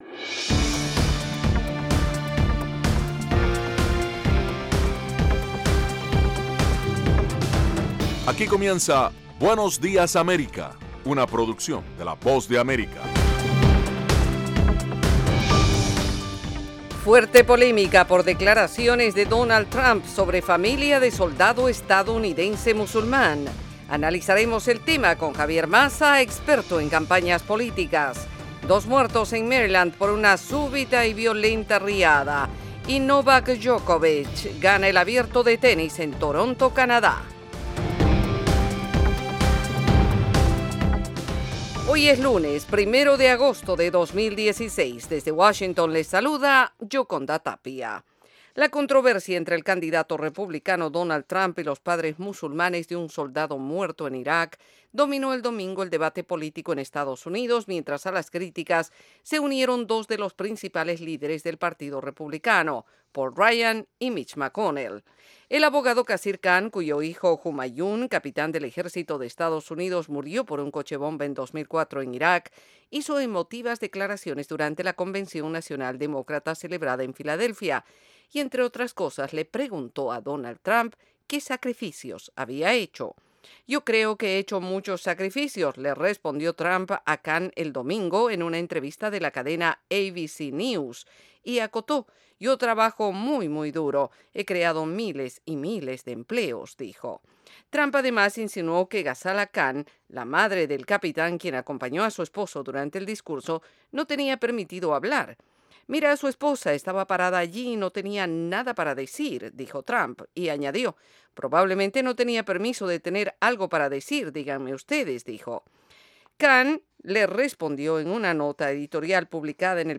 Información ágil y actualizada en las voces de los protagonistas con todo lo que sucede en el mundo, los deportes y el entretenimiento.